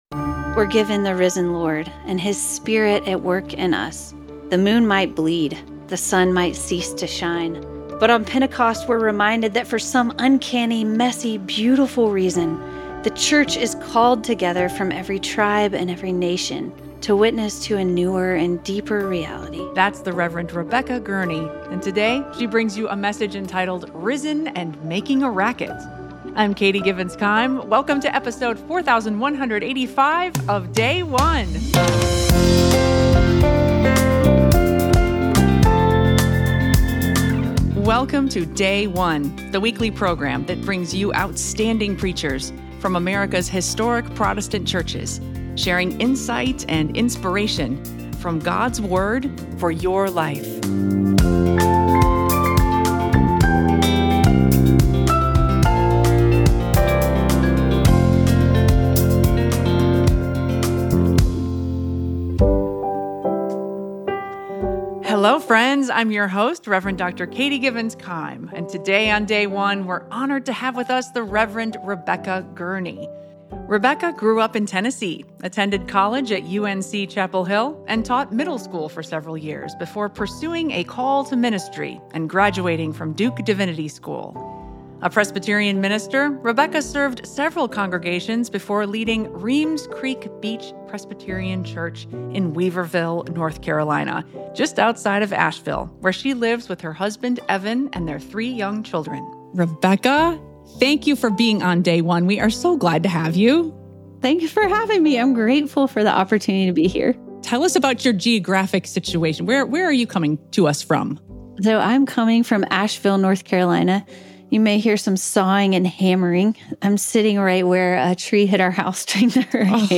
Day of Pentecost - Year C Acts 2:1-21, 32-33, 36-39